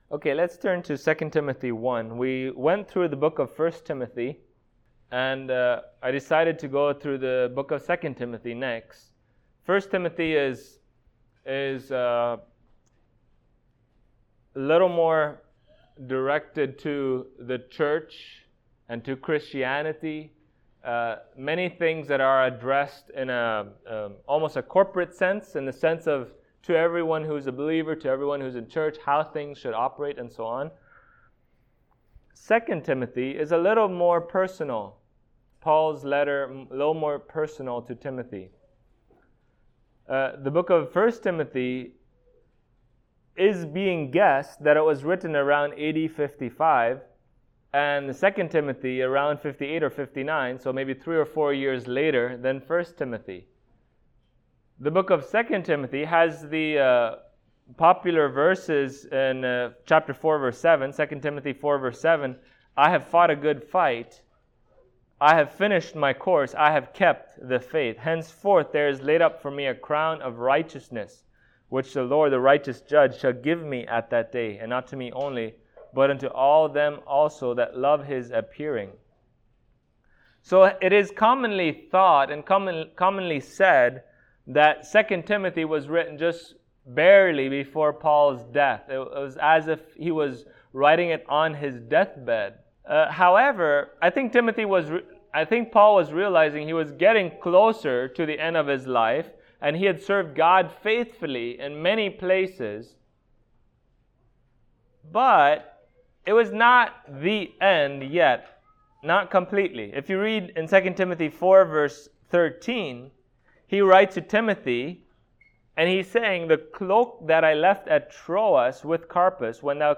2Timothy 1:1-2 Service Type: Sunday Morning As Believers